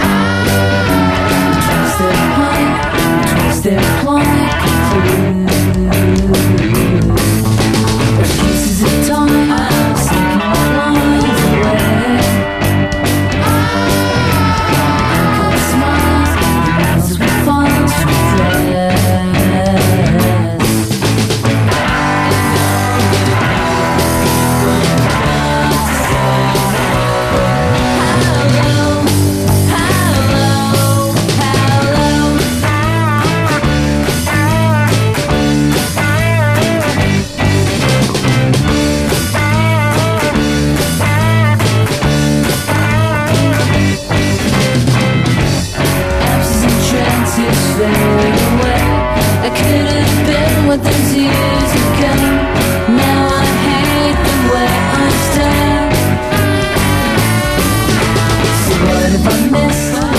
ALT./EXPERIMENTAL
エクスペリメンタルやジャズな要素が詰まった3枚組編集盤！
打ちまくりのドラムやパーカッションに、マッドな電子音や華麗なスキャットが散りばめられた